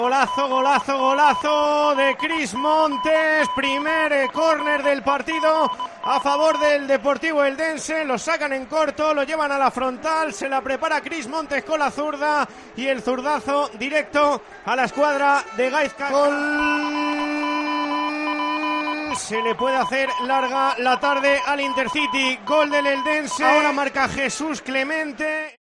Así vivimos el partido del Nuevo Pepico Amat en Tiempo de Juego Alicante
Sonidos de la derrota del Intercity en Elda